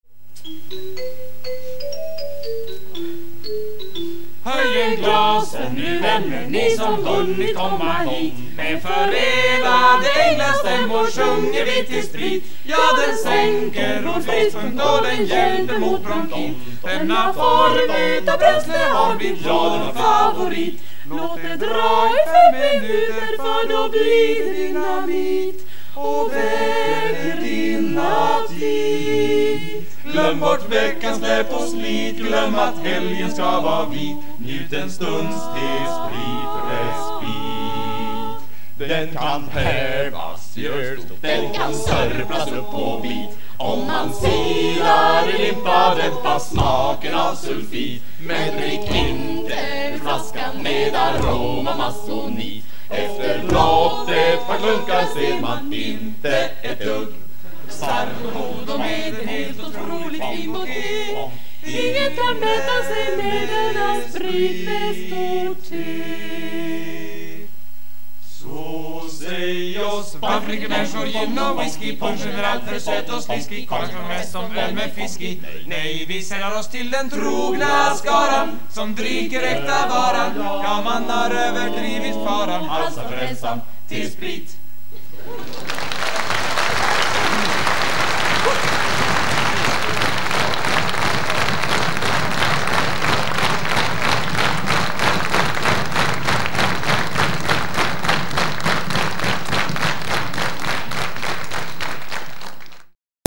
Låtar som av någon anledning valts ut från spex-93.